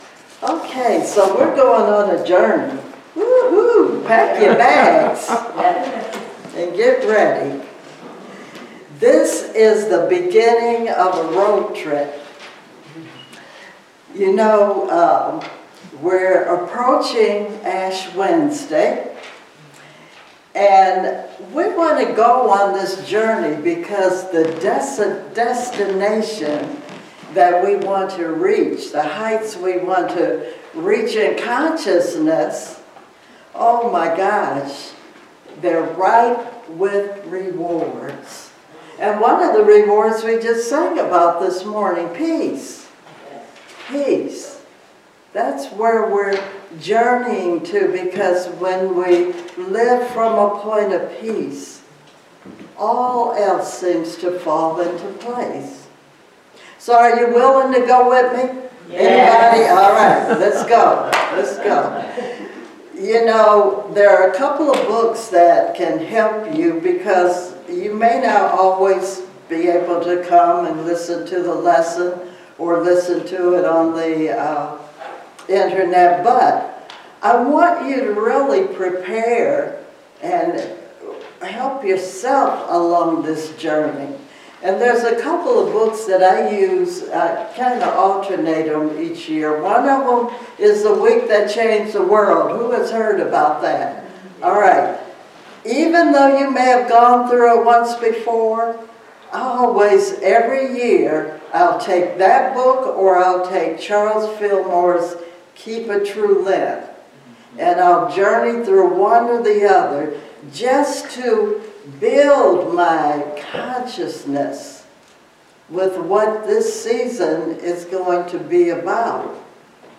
Series: Sermons 2024